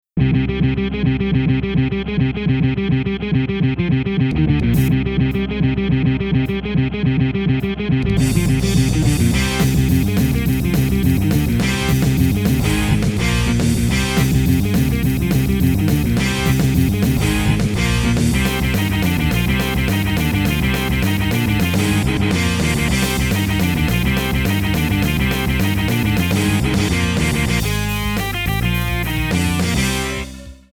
爽やかじゃない、ダークなロック。(introのみ)